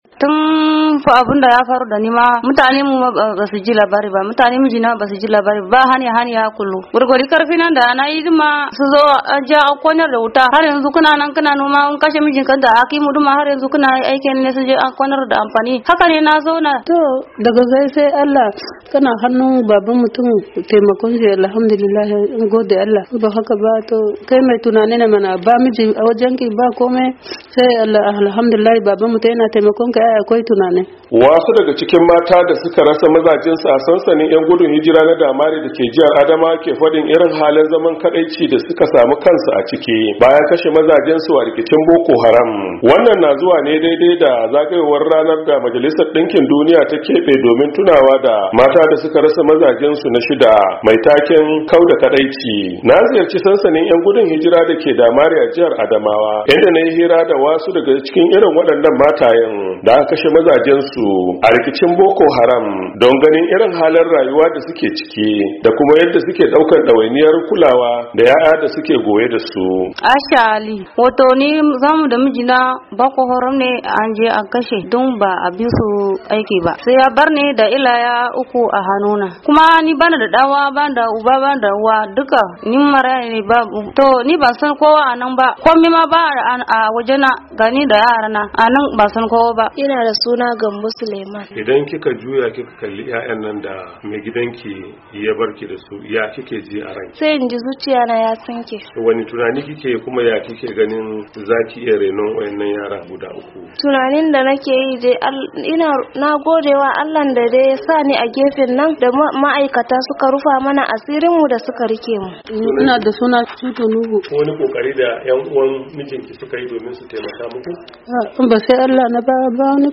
A cikin wannan rahoton